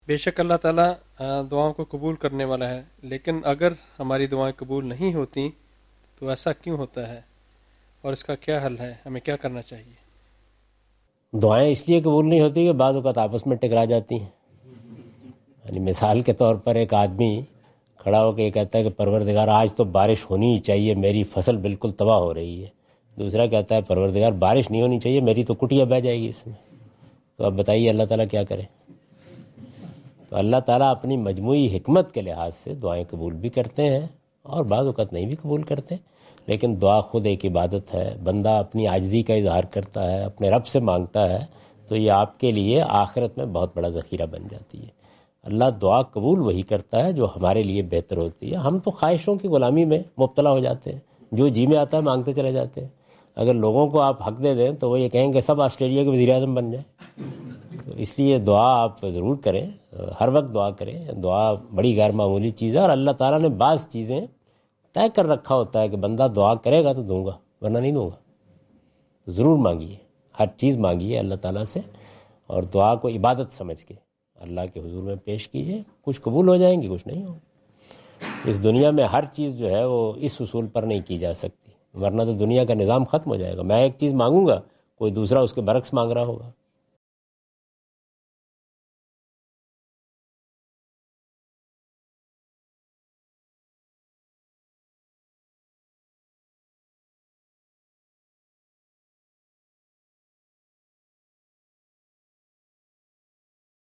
Javed Ahmad Ghamidi answer the question about "Prayers Going Unheard" during his Australia visit on 11th October 2015.
جاوید احمد غامدی اپنے دورہ آسٹریلیا کے دوران ایڈیلیڈ میں "دعا کی عدم مقبولیت" سے متعلق ایک سوال کا جواب دے رہے ہیں۔